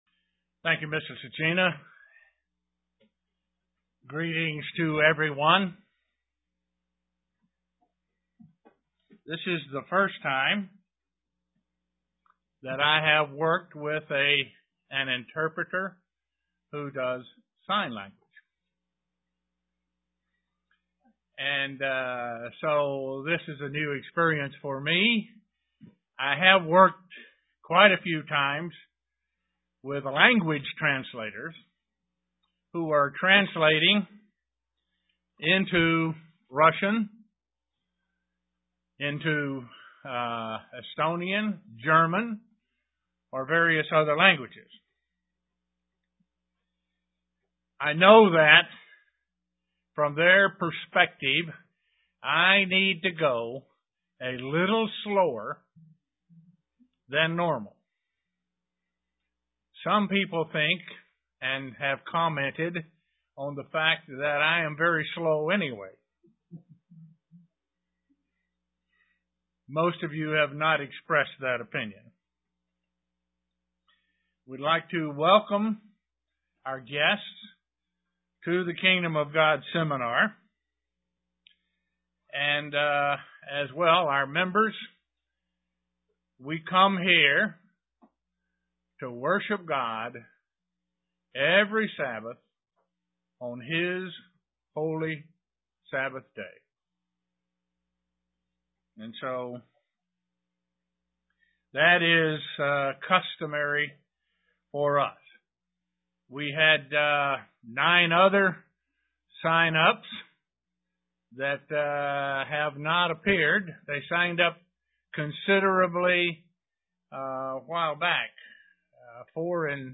Given in Elmira, NY
Print The central theme of the Bible is the Kingdom of God UCG Sermon Studying the bible?